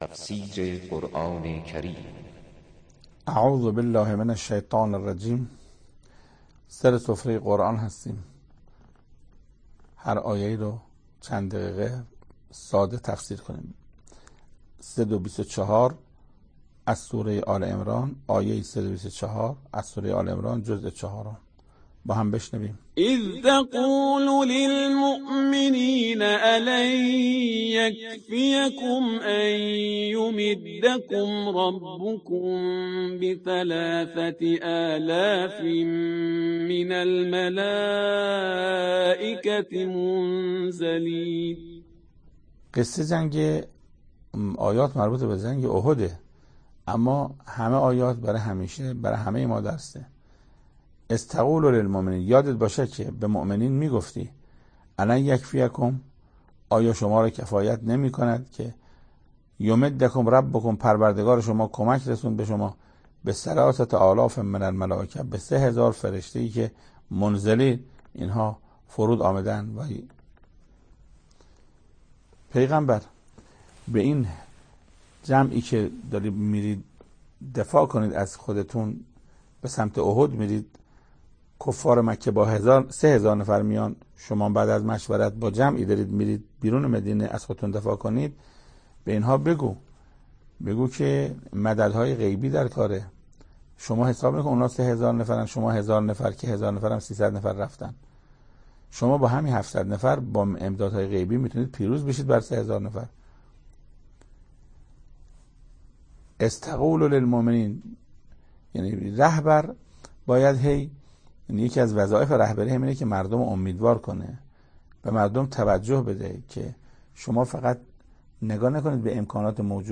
تفسیر صد و بیست و چهارمین آیه از سوره مبارکه آل عمران توسط حجت الاسلام استاد محسن قرائتی به مدت 5 دقیقه